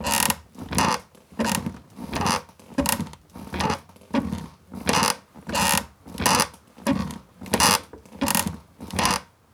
chair_frame_metal_creak_squeak_loop.wav